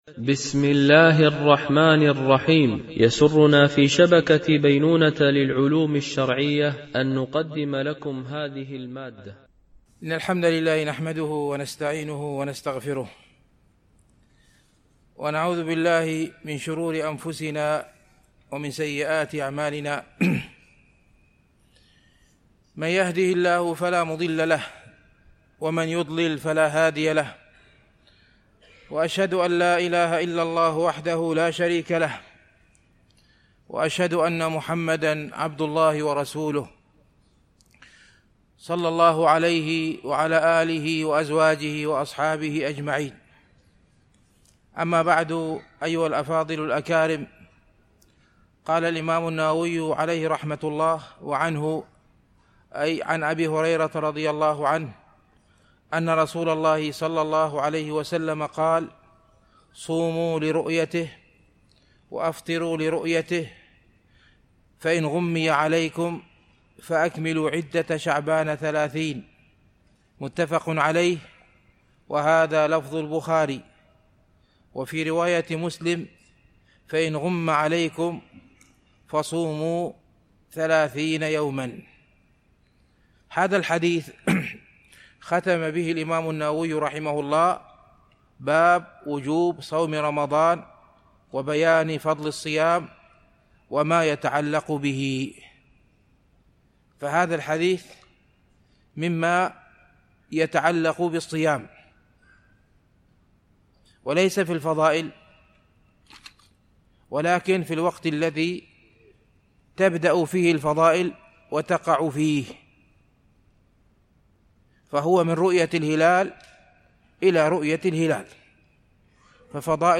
شرح رياض الصالحين – الدرس 324 ( الحديث 1229-1230)